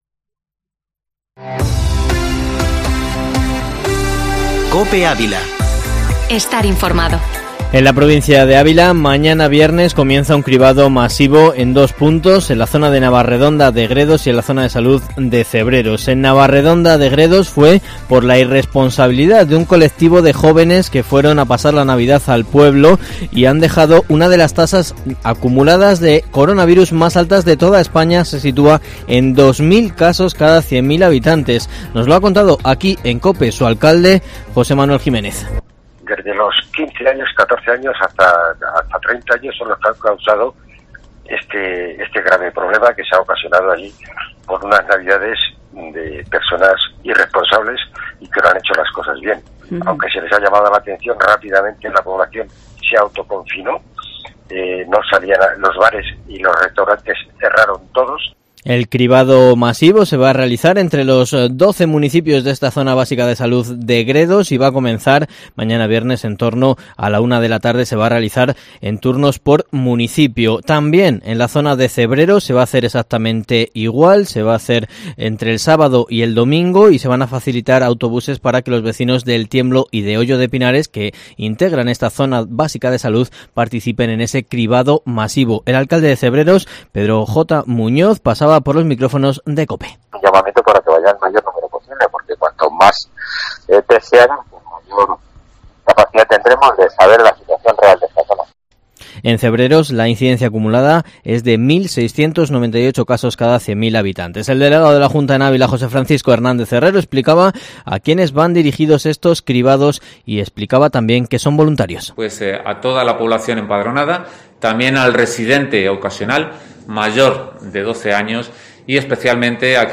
Informativo matinal Herrera en COPE Ávila 14/01/2021